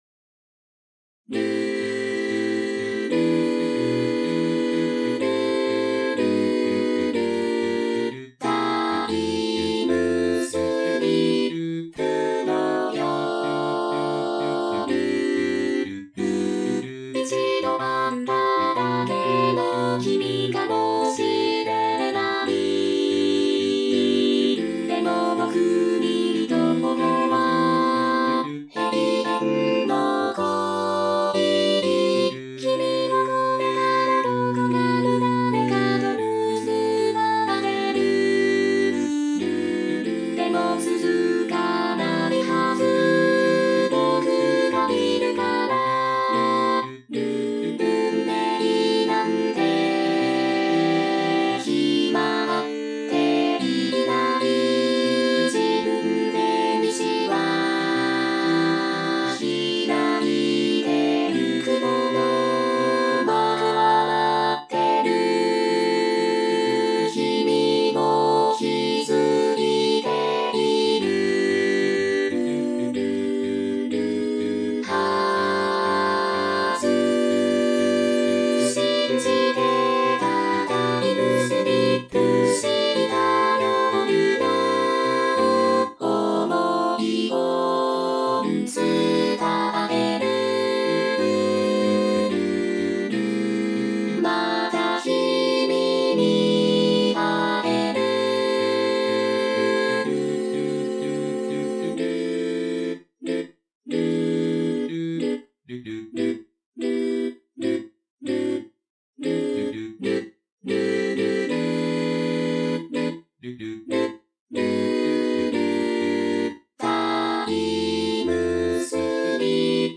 noteでのコラボ曲を7枚ア・カペラで。
「Sagittaria Trifolia Choir」は私のUTAU（ボカロ）音源によるコーラスチームです。